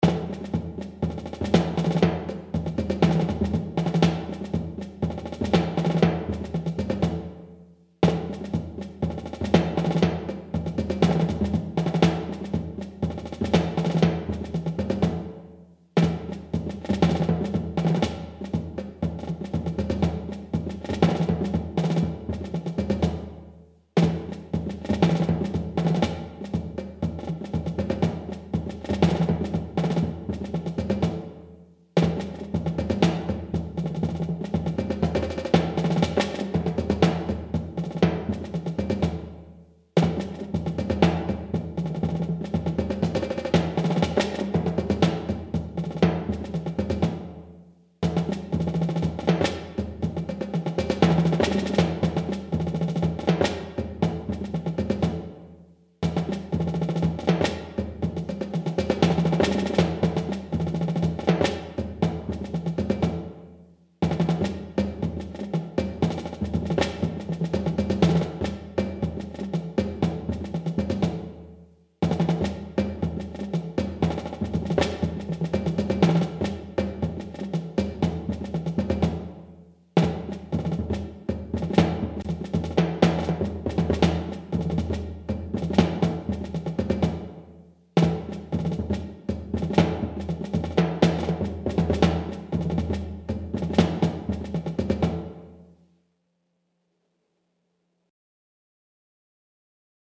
Ongestemd Marcherend Slagwerk
Snare drum Bass drum